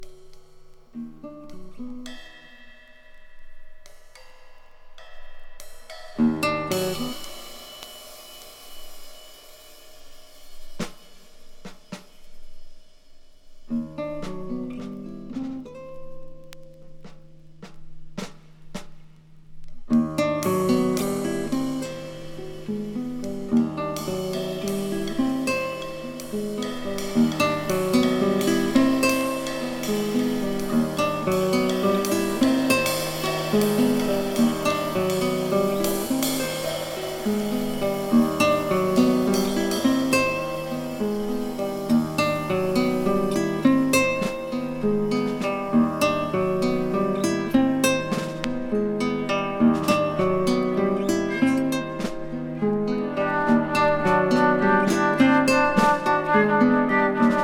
正にタイトル通りの幽玄トラック。'77年ノルウェー録音。